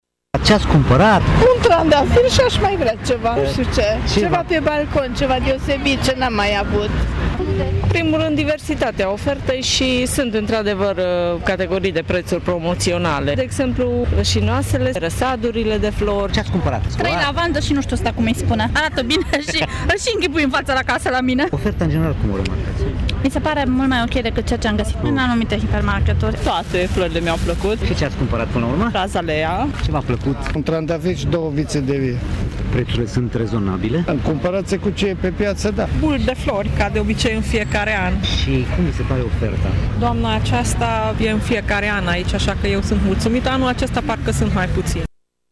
Cumpărătorii cu care am stat de vorbă au fost mulțumiți atât de varietatea ofertei cât și de prețuri. Sunt cetățeni care de mai mulți ani folosesc oportunitatea acestui târg de flori, pentru a-și orna grădinile: